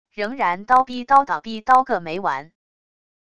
仍然叨逼叨叨逼叨个没完wav音频生成系统WAV Audio Player